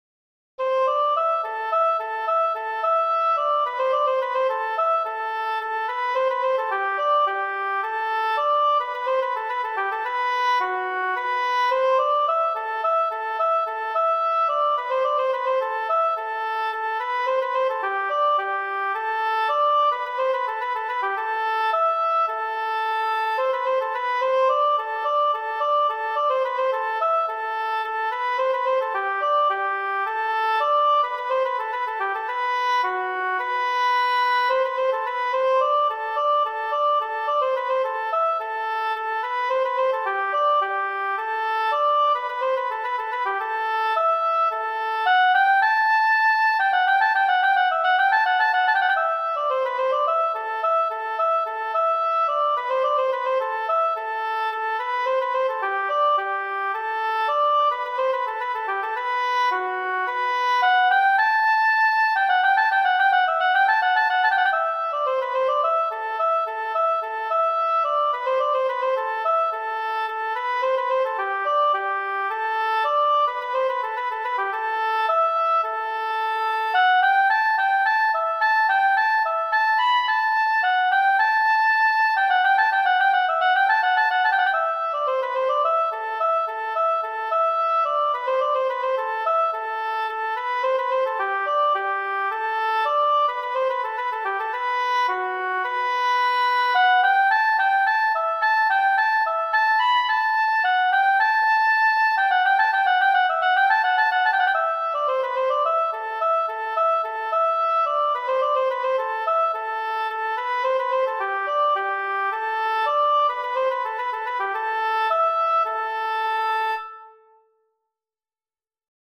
for solo instrument e.g. oboe